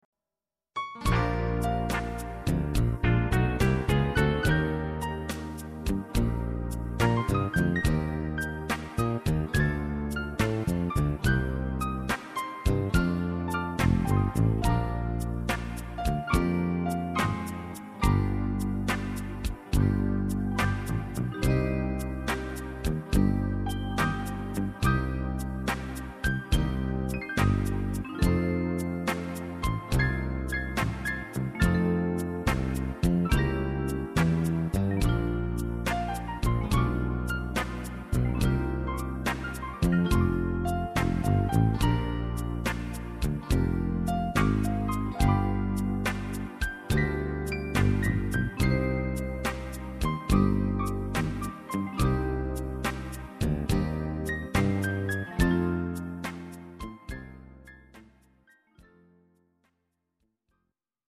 instr. Flute